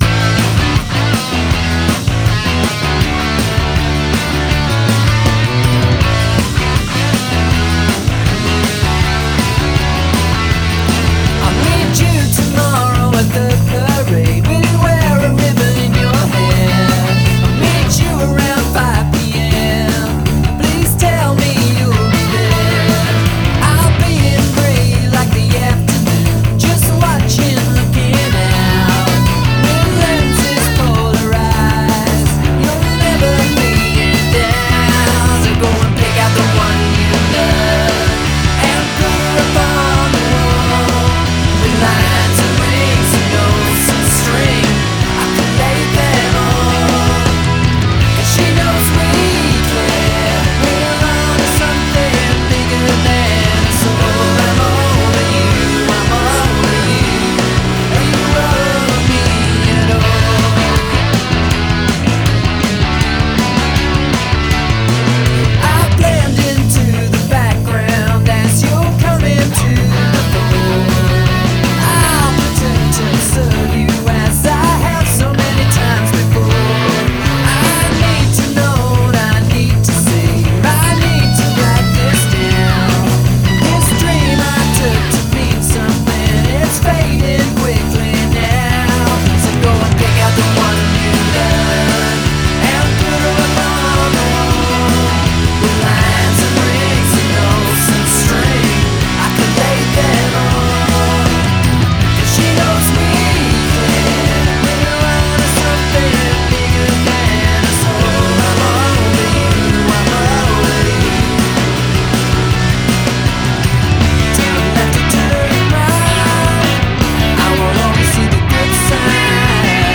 delightfully jumpy pop